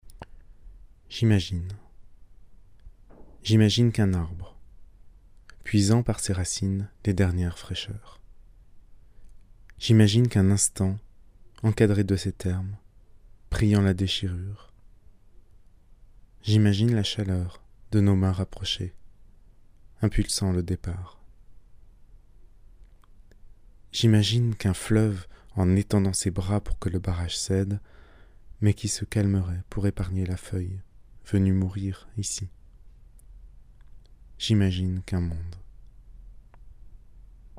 Il contient 23 poèmes lus par votre serviteur en mp3.